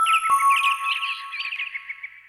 Clock Bell01.aac